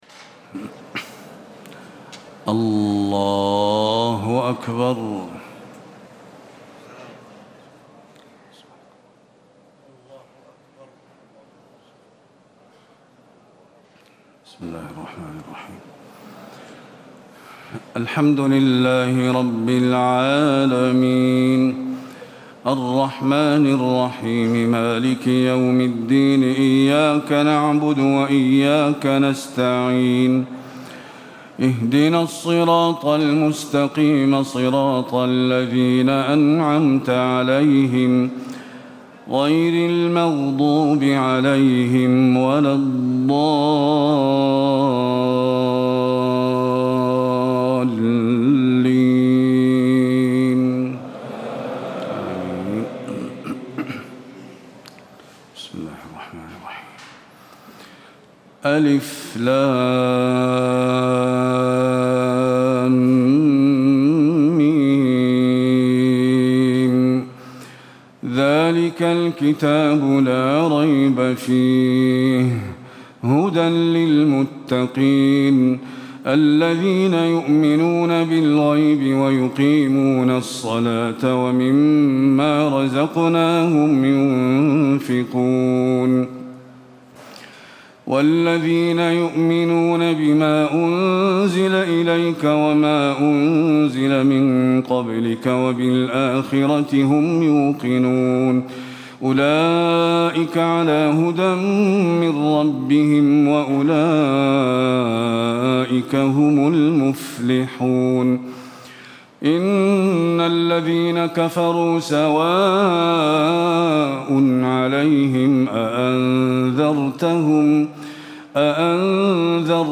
تهجد ليلة 21 رمضان 1437هـ من سورة البقرة (1-91) Tahajjud 21 st night Ramadan 1437H from Surah Al-Baqara > تراويح الحرم النبوي عام 1437 🕌 > التراويح - تلاوات الحرمين